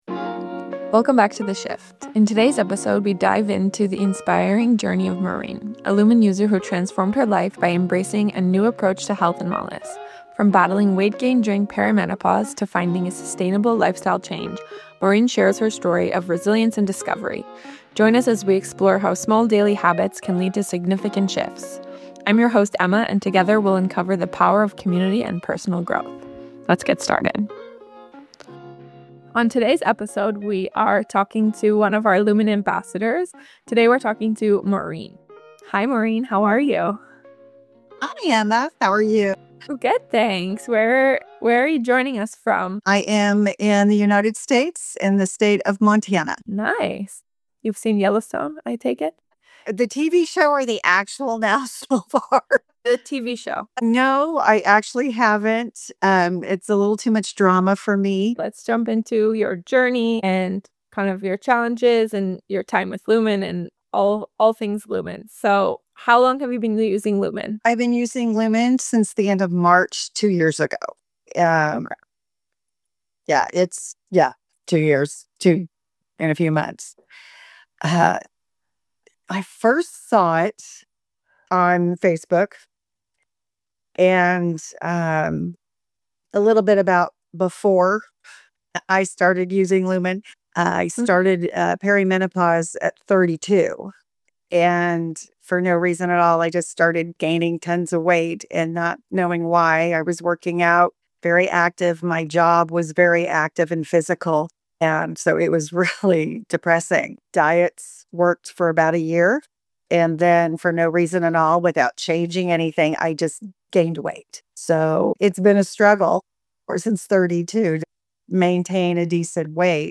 Tune in for an empowering conversation about resilience, self-care, and the power of trusting the process.